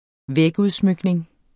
Udtale [ ˈvεːg- ]